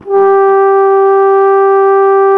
TENORHRN G 2.wav